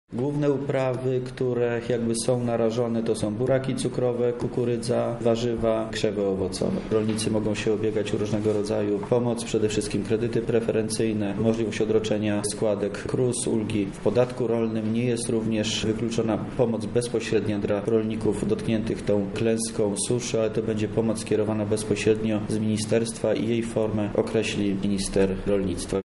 – tłumaczy wojewoda lubelski Wojciech Wilk.